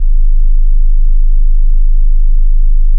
BASS 8.wav